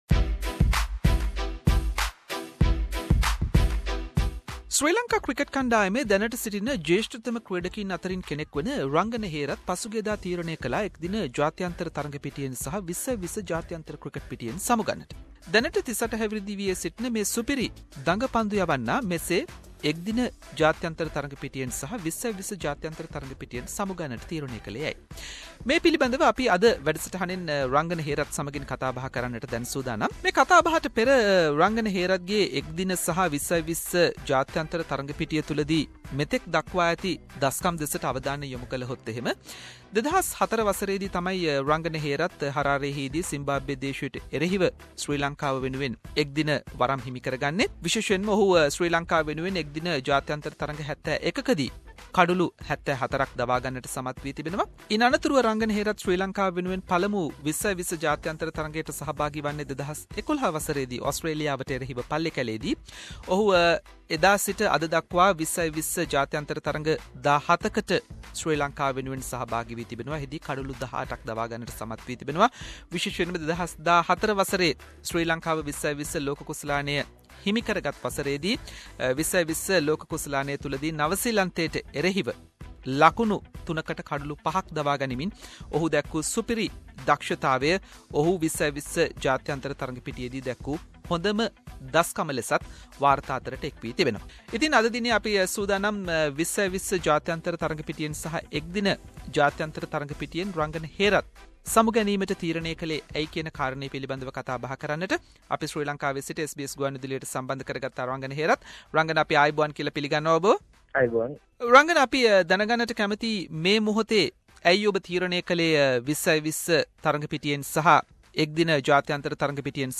Special interview with Sri Lankan left arm spinner Rangana Hearth regarding his retirement from ODI cricket and T20 international cricket.